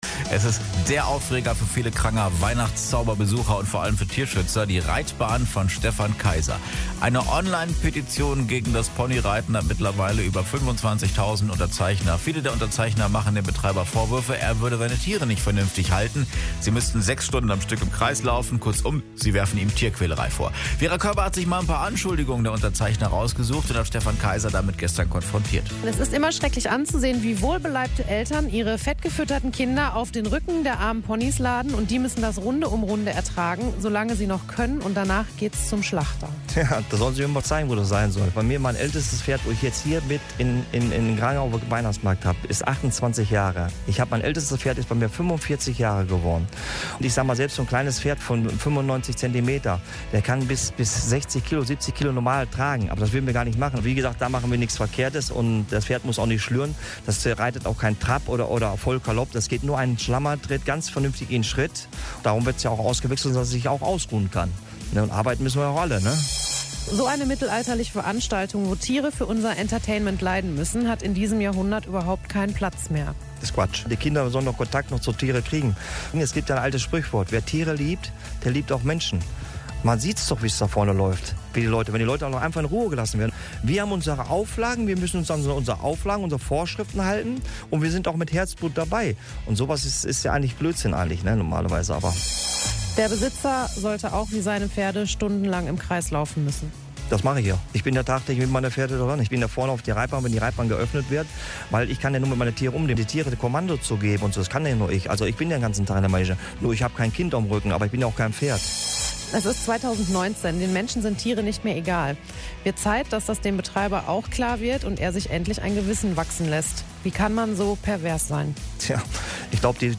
mitschnitt-pony-interview.mp3